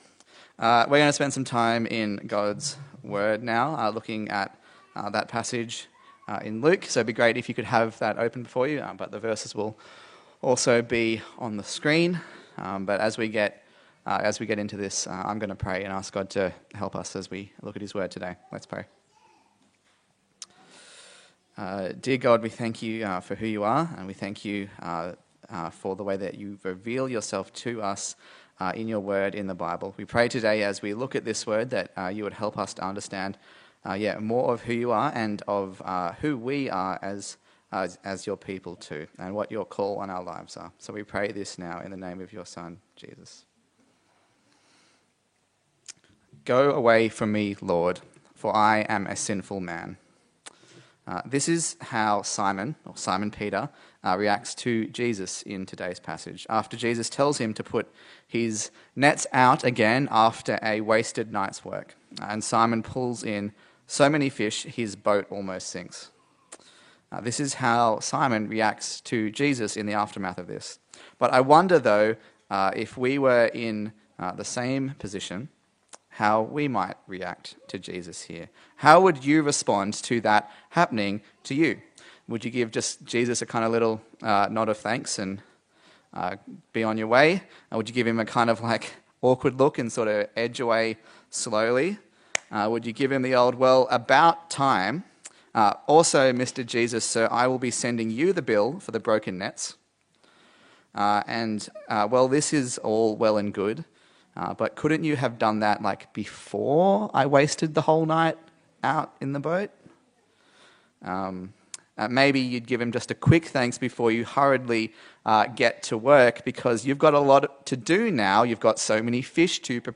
Luke Passage: Luke 5:1-16 Service Type: Sunday Service